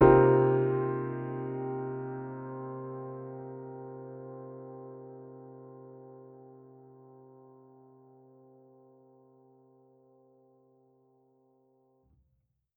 Index of /musicradar/jazz-keys-samples/Chord Hits/Acoustic Piano 1
JK_AcPiano1_Chord-Cm11.wav